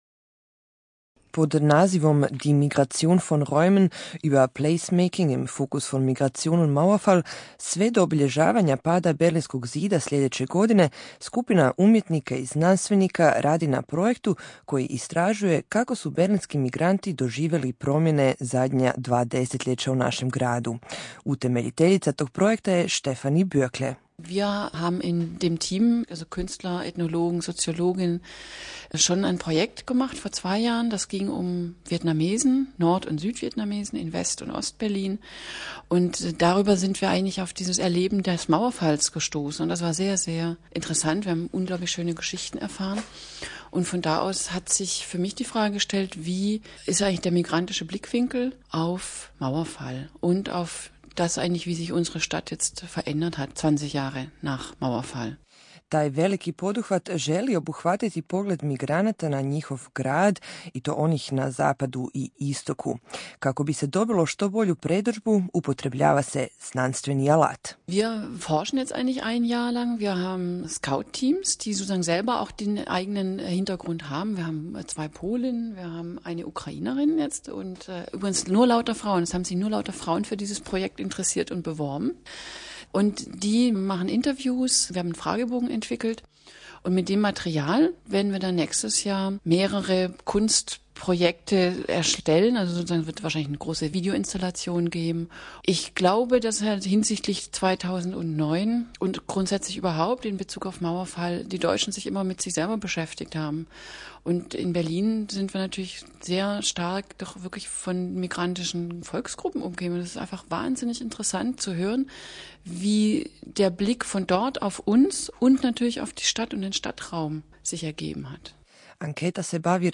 Radiobeitrag 27. Juni 2008 auf multikulti